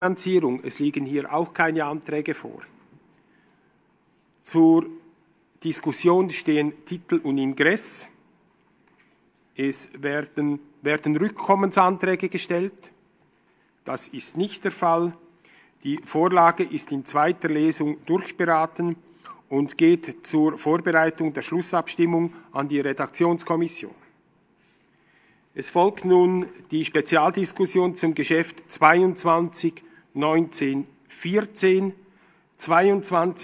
Session des Kantonsrates vom 18. bis 20. Mai 2020, Aufräumsession